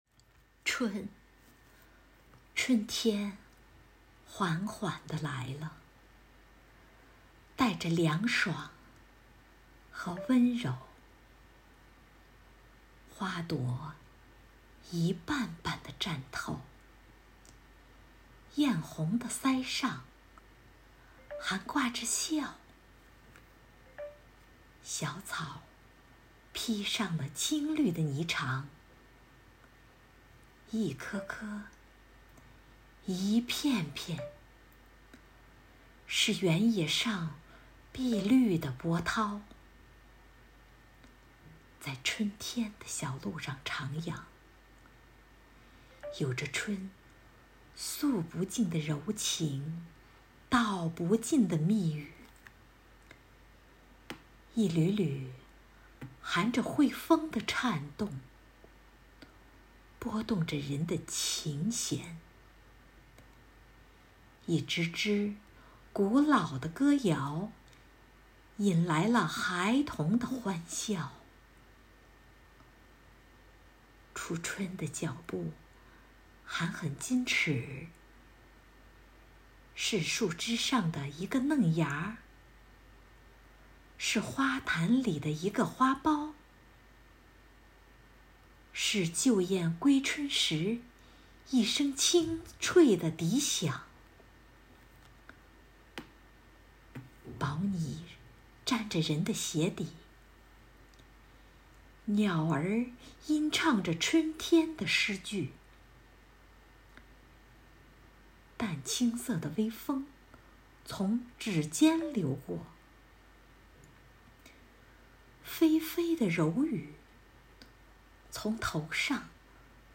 暨诵读心声支队第1场幸福志愿者朗诵会